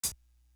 Mpk Hat.wav